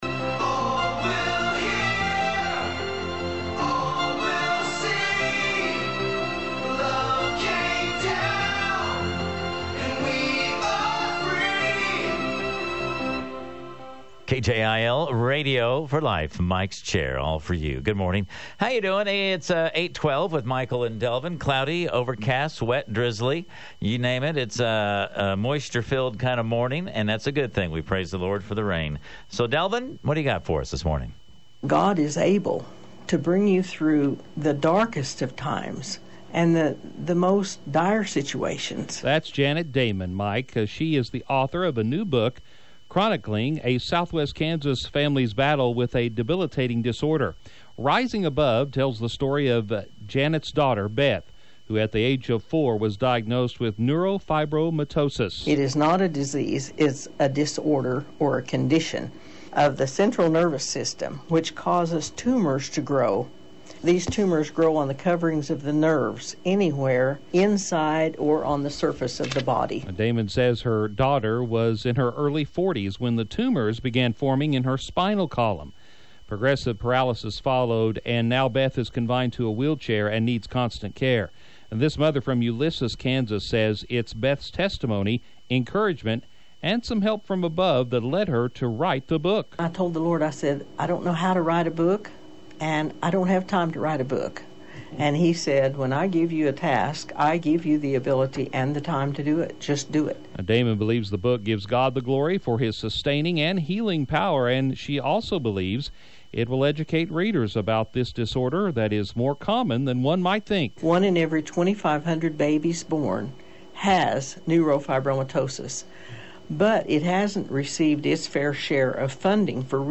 And Thursday I have the interview with our local radio station.
I assume you had a longer interview and the announcer cut out only the few parts he wanted for the short segment.